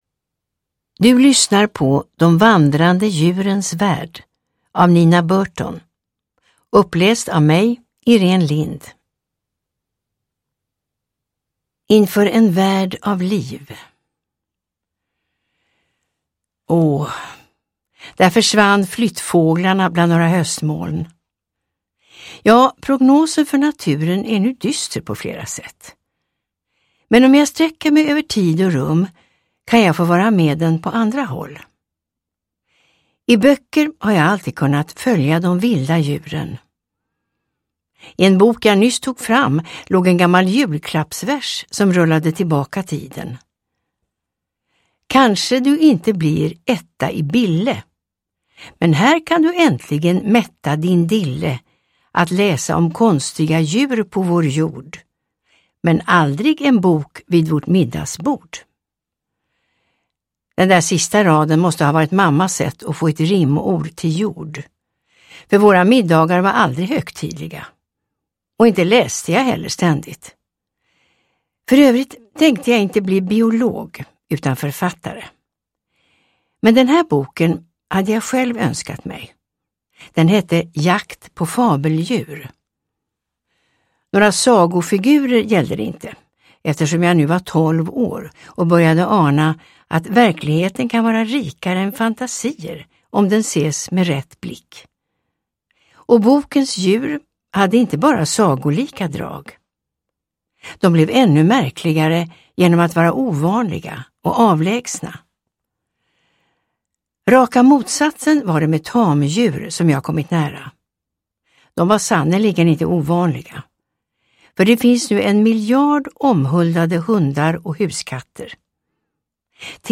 De vandrande djurens värld : en upptäcktsfärd mellan ishav, savann och stängsel – Ljudbok